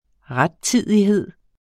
Udtale [ ˈʁadˌtiðˀiˌheðˀ ]